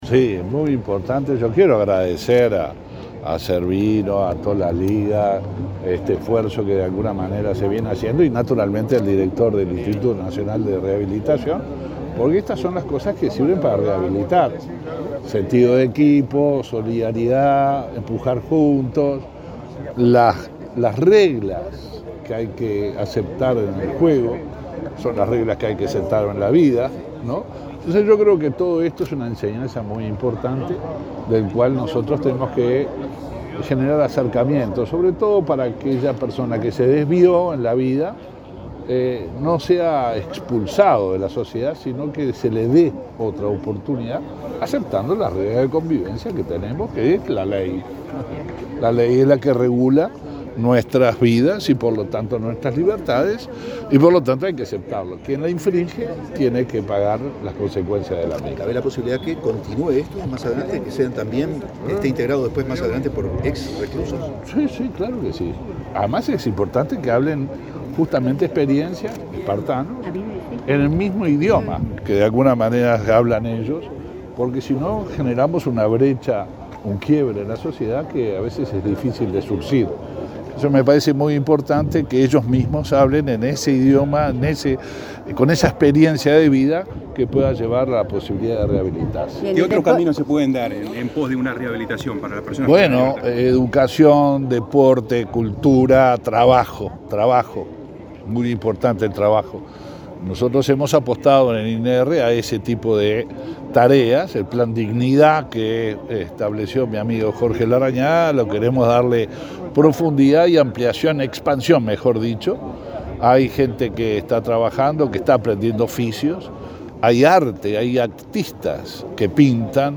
Declaraciones a la prensa del ministro del Interior, Luis Alberto Heber
El ministro del Interior, Luis Alberto Heber, dialogó con la prensa antes de presenciar un encuentro internacional de rugby inclusivo entre dos